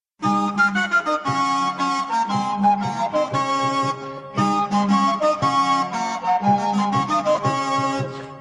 Estampie.mp3